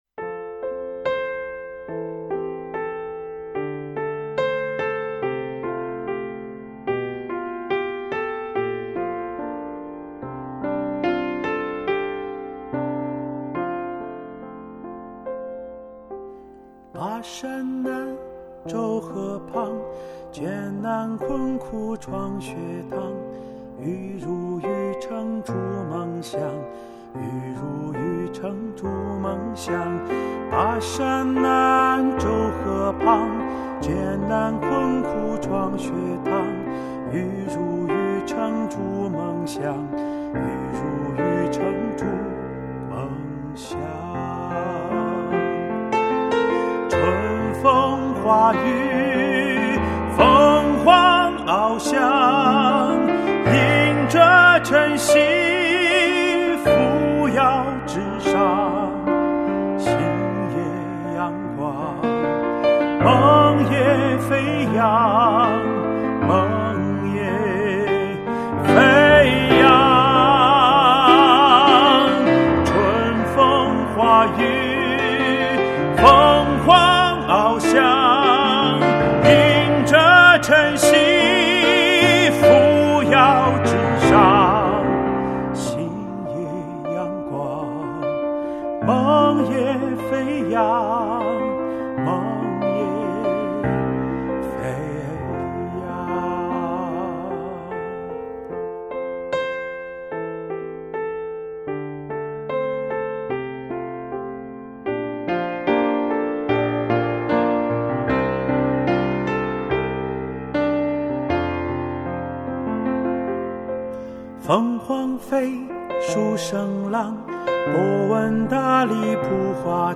《凤凰欲飞》词曲清新隽秀，旋律优美，充满青春气息，饱含学校历史文化和时代激情。
校歌f男声.MP3